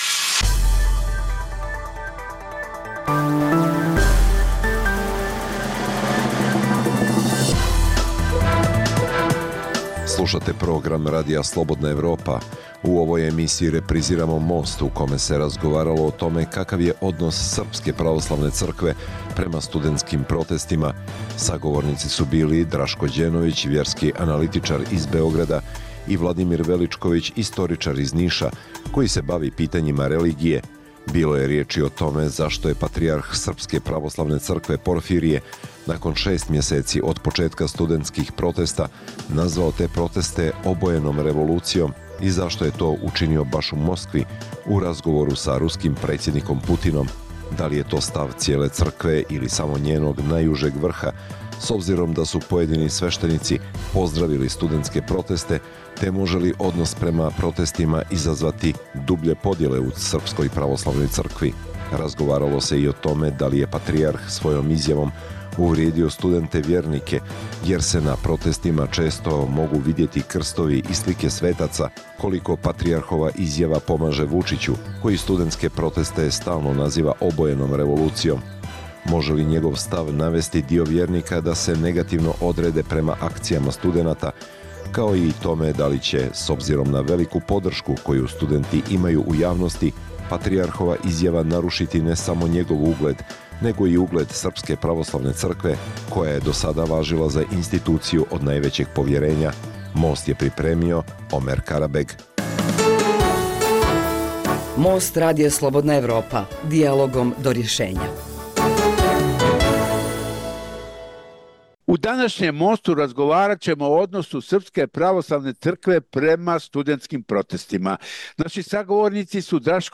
Dijaloška emisija o politici